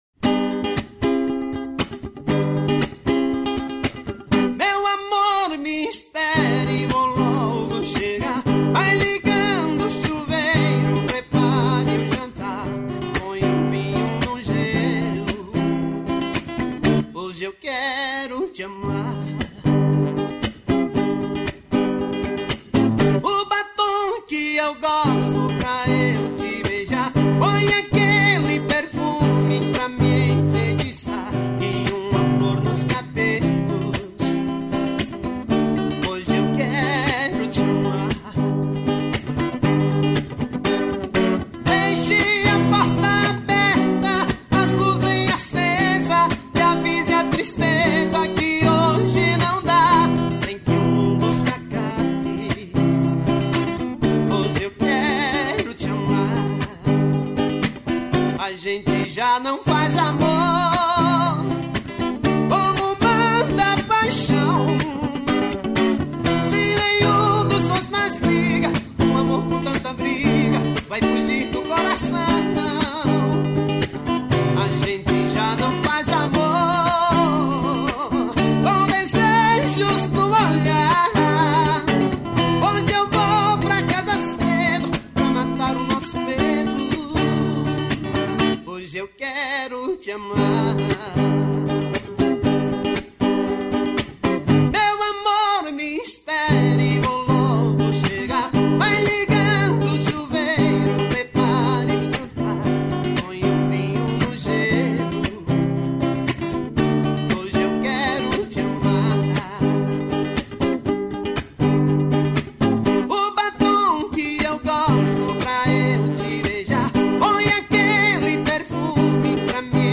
ao vivo voz e violão